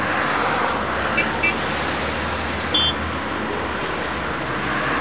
00041_Sound_traffic.mp3